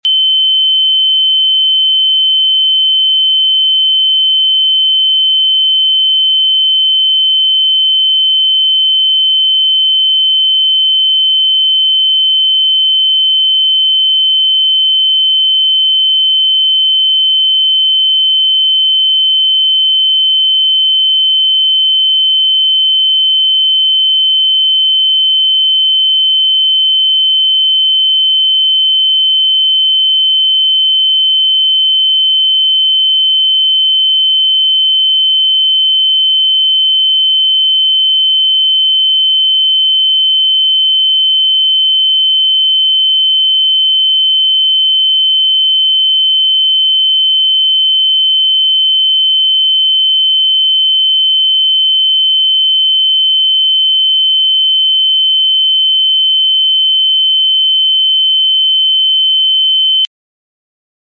Navigate the soundscape of 3071 Hz! Let 60 seconds transport you, feel the sonic waves, and share your revelations.